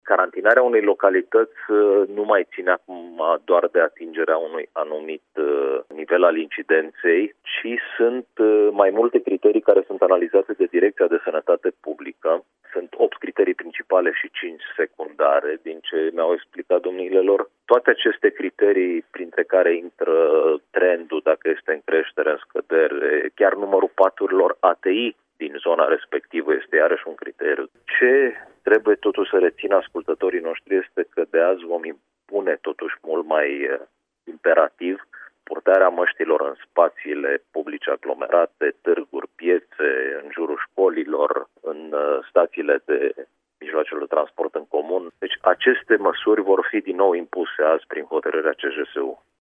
Decizia va fi luată astăzi în ședința Comitetului Județean pentru Situații de Urgență, care așteaptă și o clarificare de la București privind legislația, care permite participările la nunți sau botezi, până la pragul de 6 la mie, a precizat, la Radio Timișoara, subprefectul de Timiș, Ovidiu Drăgănescu.